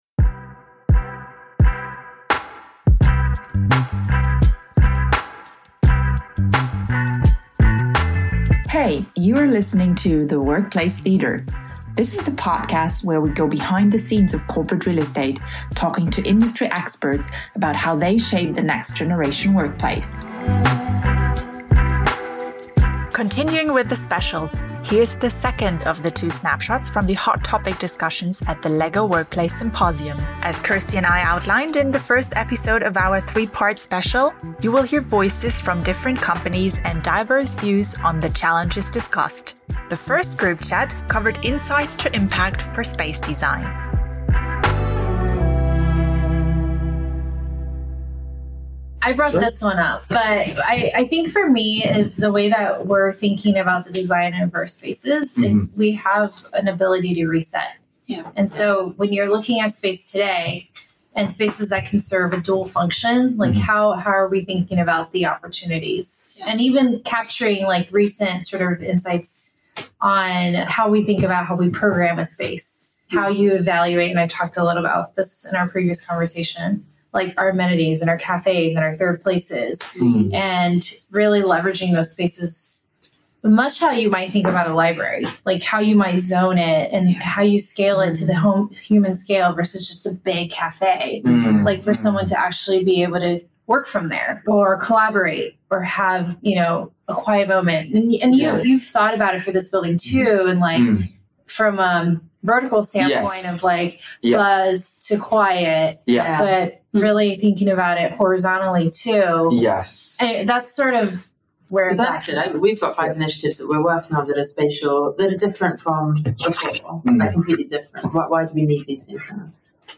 In the third part of our special around the LEGO workplace symposium we are tuning into the group exchanges on the two hot topics of insights to impact spatial design and creating purposeful meetings.